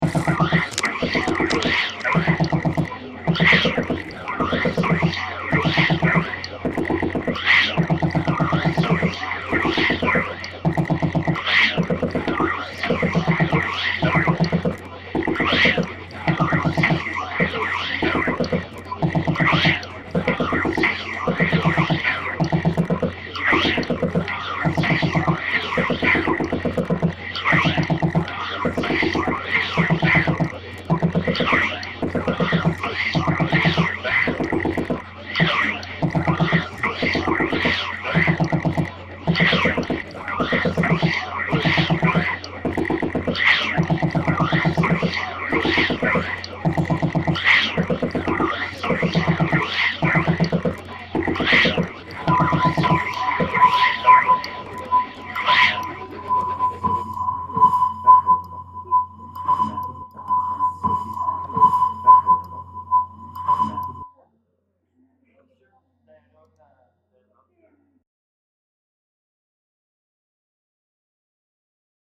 Workshop Sound Composition Overlapping layers of sound recorded from the studio environment